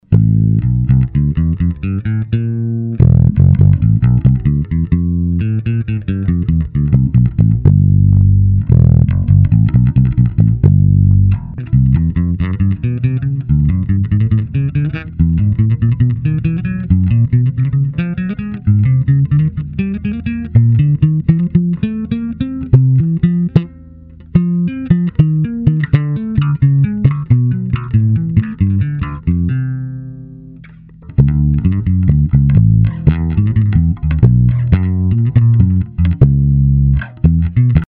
Nakonec jsem po mnoha diskusích na zahraničních fórech namotal sadu Rotosound Swing Bass a ty tomu sedí.
První ukázka je hraná prstem, jen kobylkový snímač, ta druhá trsátko, kobylkový snímač, a třetí trsátkem, oba snímače .